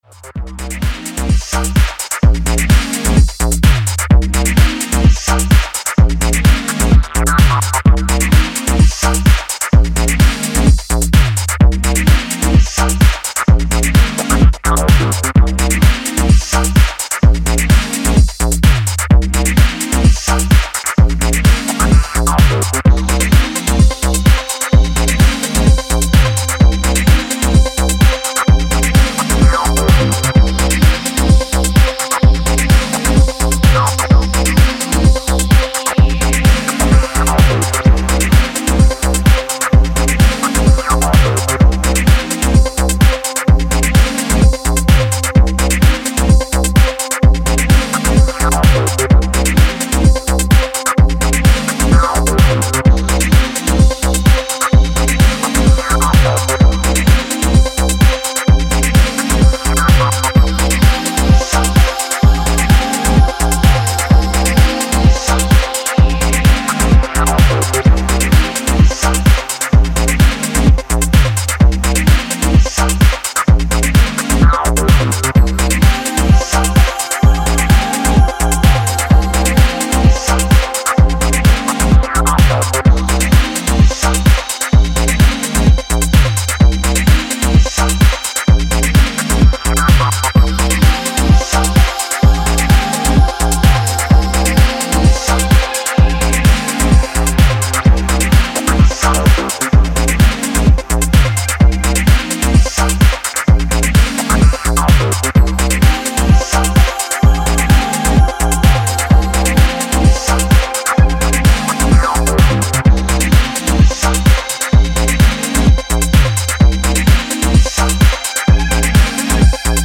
コズミックでデトロイティッシュなムードのエレクトロ・ファンク